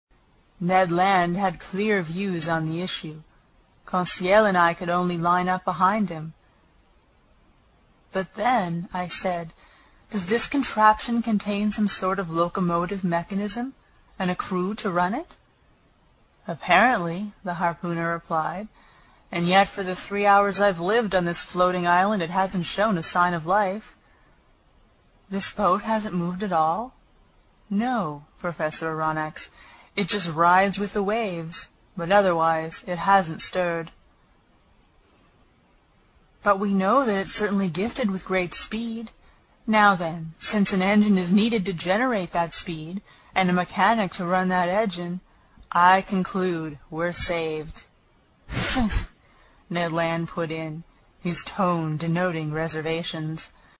英语听书《海底两万里》第91期 第7章 一种从未见过的鱼(14) 听力文件下载—在线英语听力室